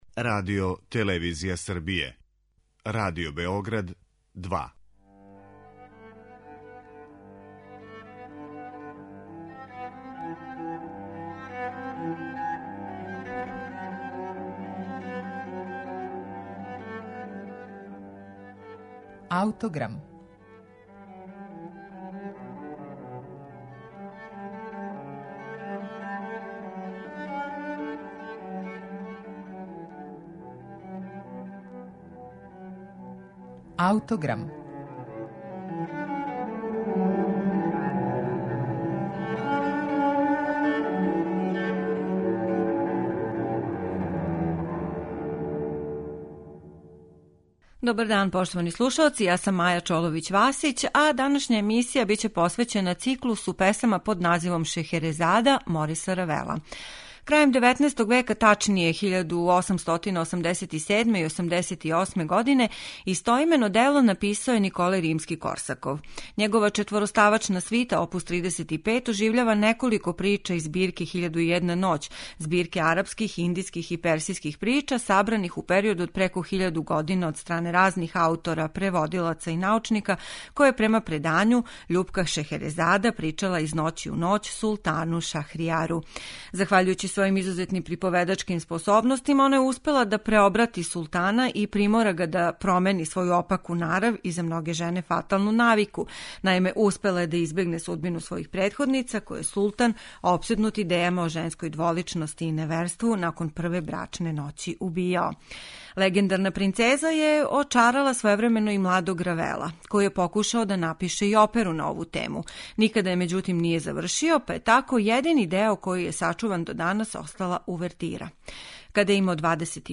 Циклус чине песме "Азија", "Зачарана свирала" и "Равнодушан", а слушаћете их у извођењу Џеси Норман и Лондонског симфонијског оркестра, којим диригује Колин Дејвис.